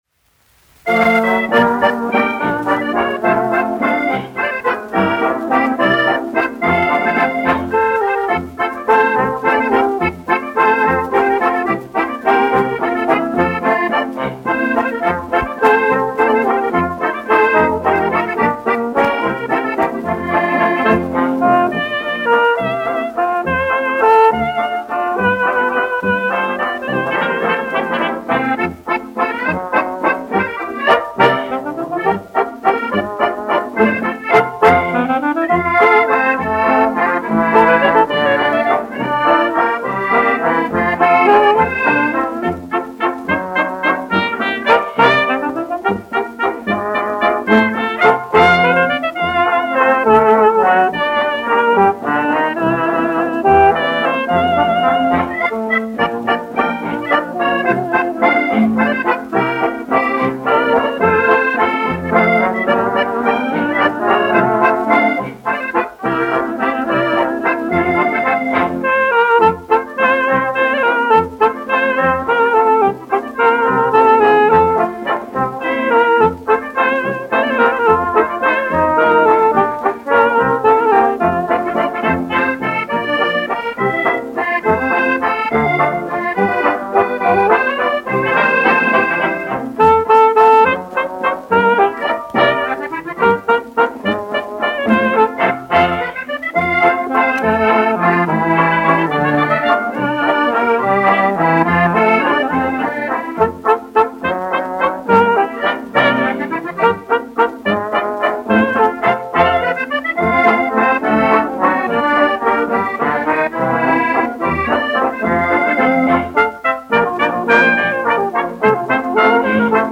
1 skpl. : analogs, 78 apgr/min, mono ; 25 cm
Sarīkojumu dejas
Latvijas vēsturiskie šellaka skaņuplašu ieraksti (Kolekcija)